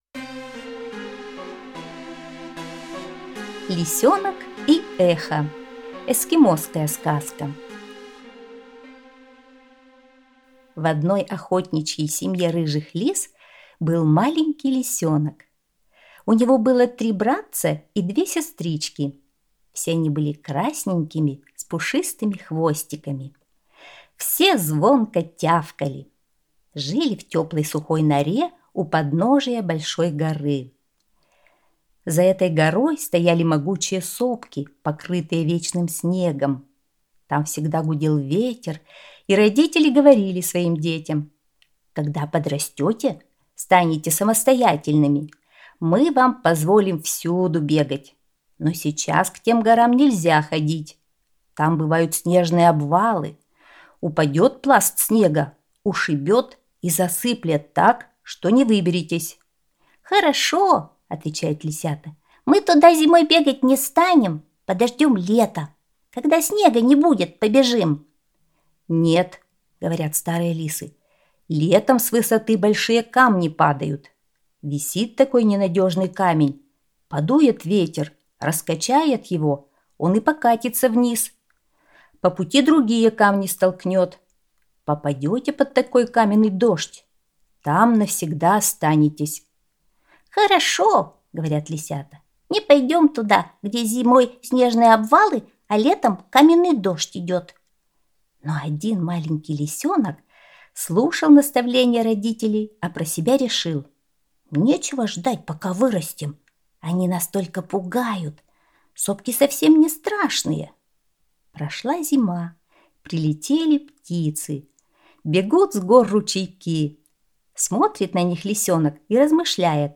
Эскимосская аудиосказка